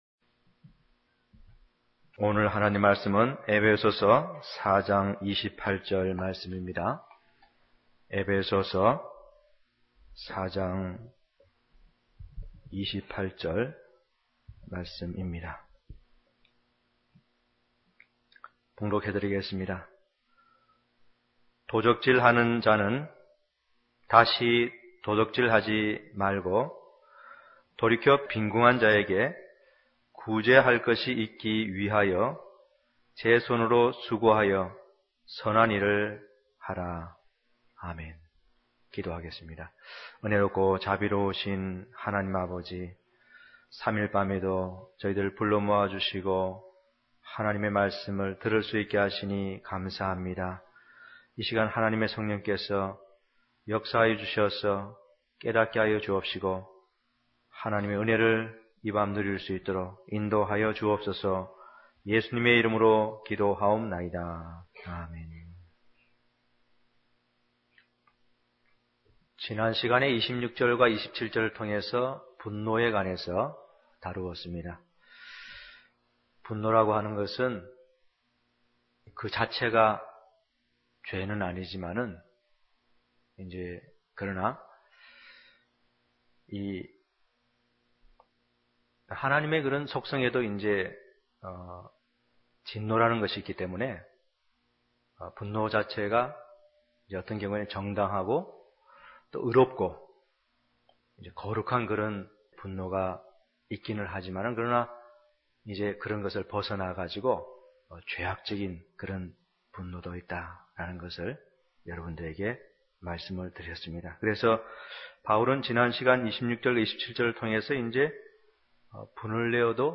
강해설교 13 페이지 | 진리교회